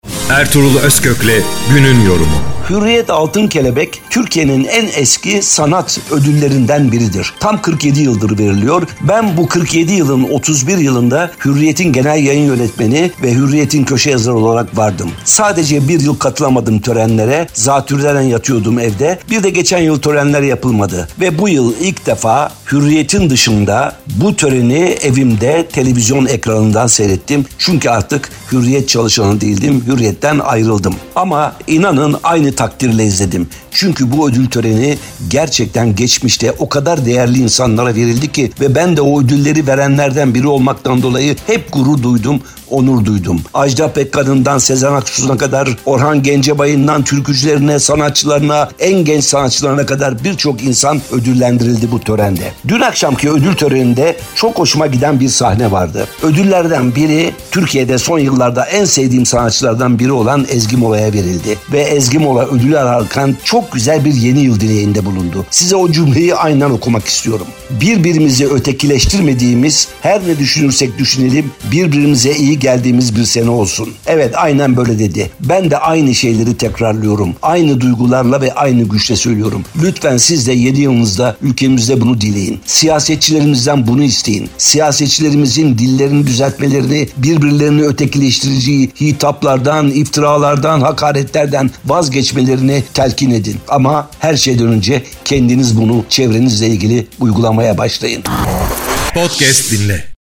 ERTUGRUL-OZKOK_6-ARALIK-YORUM-JINGLELI.mp3